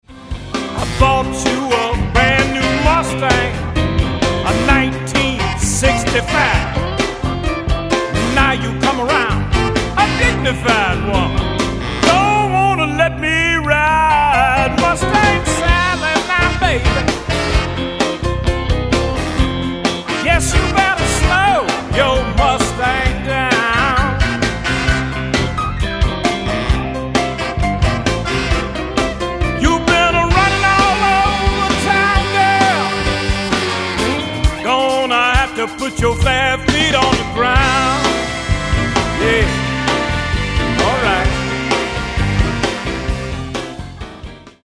The Best in Soul, Rhythm & Blues, and Carolina Beach Music